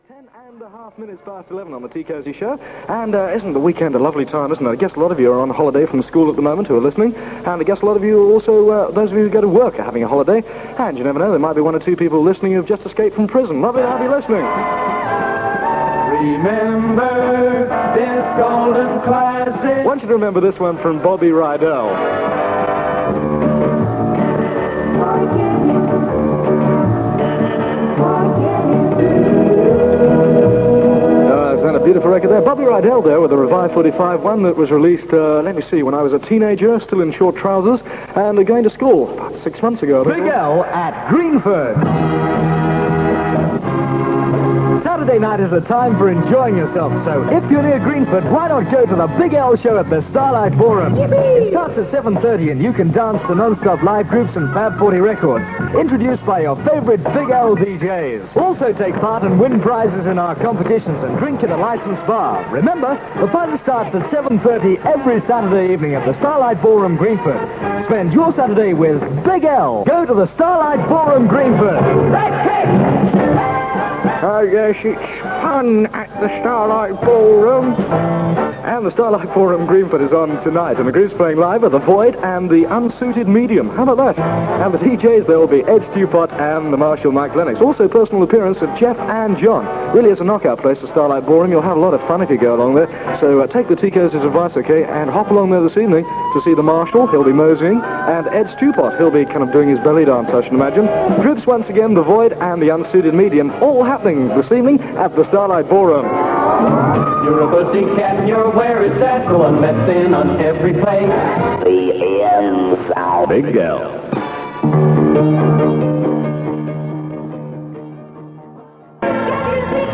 click to hear audio Tony Blackburn on Radio London on 11th February 1967, an extract from the Offshore Echo's tape Highlights Of Big L part one, used with kind permission (duration 2 minutes 42 seconds)